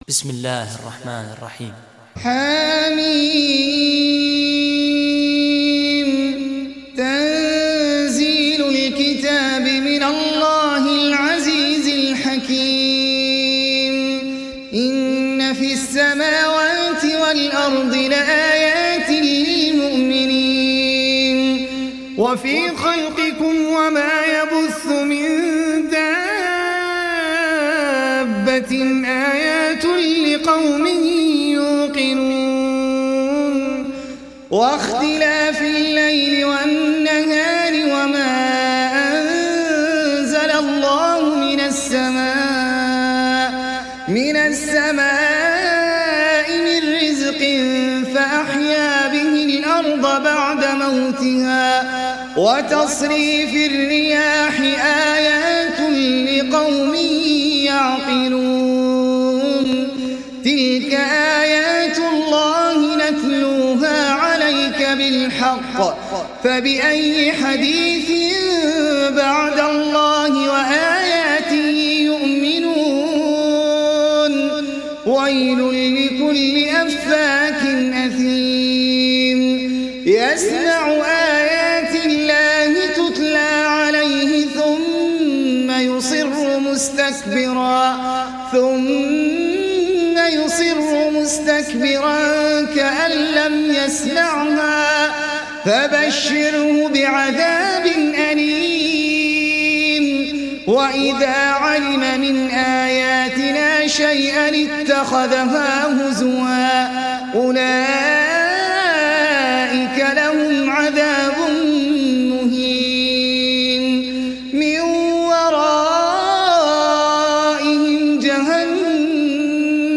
تحميل سورة الجاثية mp3 بصوت أحمد العجمي برواية حفص عن عاصم, تحميل استماع القرآن الكريم على الجوال mp3 كاملا بروابط مباشرة وسريعة